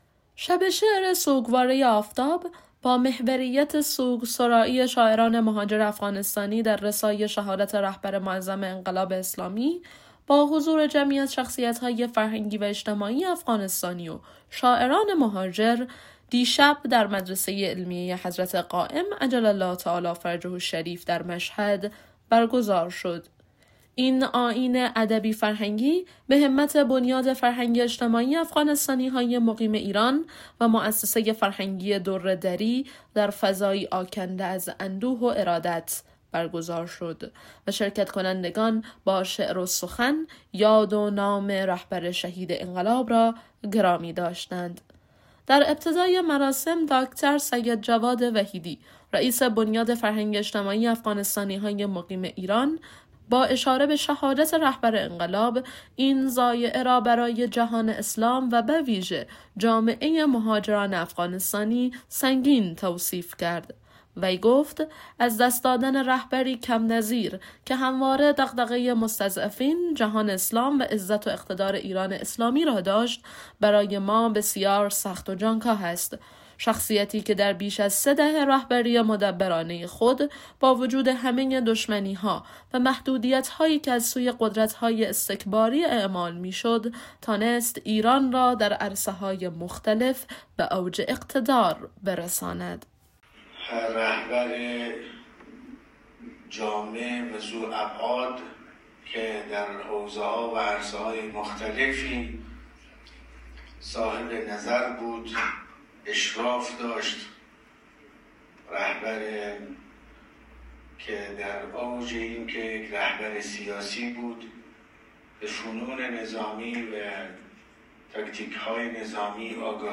سوگواره آفتاب؛ شب شعر شاعران مهاجر افغانستانی در رثای رهبر شهید انقلاب